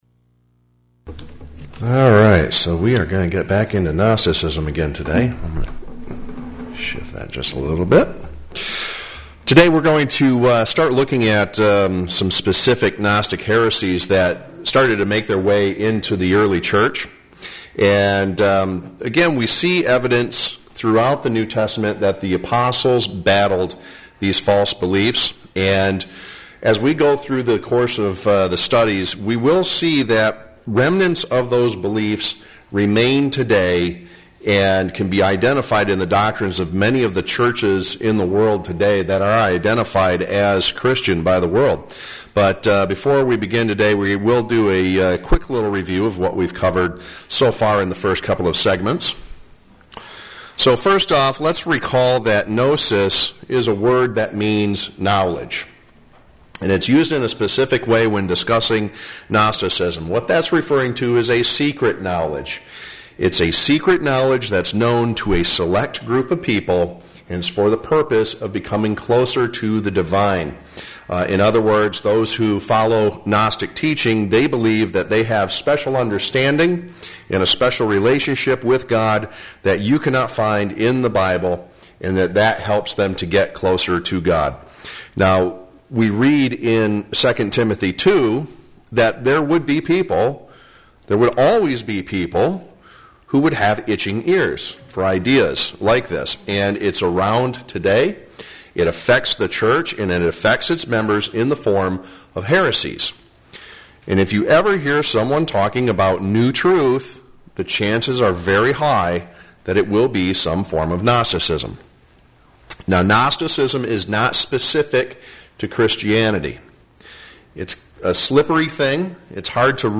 Gnosticism Bible Study: Part 3